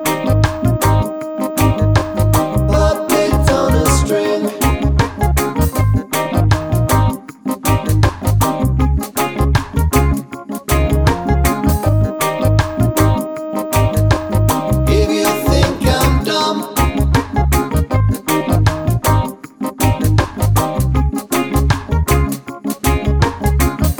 no Backing Vocals Reggae 4:09 Buy £1.50